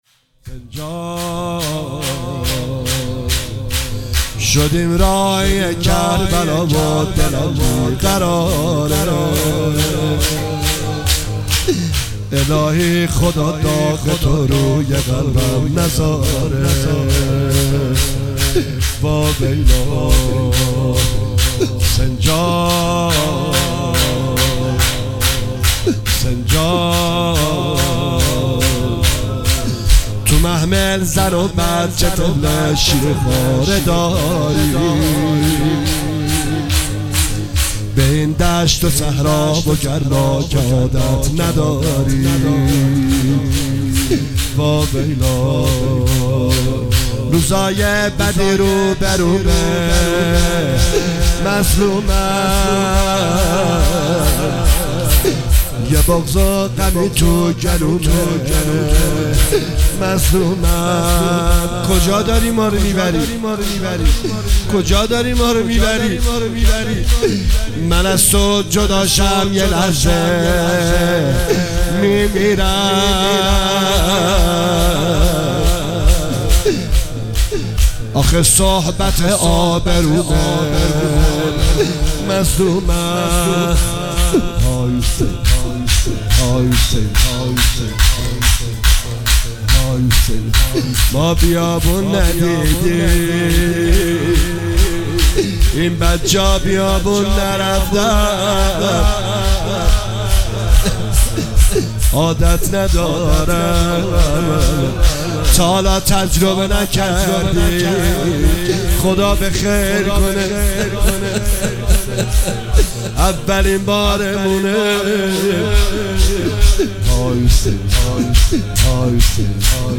مداحی شور روضه ای لطمه زنی